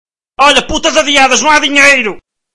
Category: Reactions Soundboard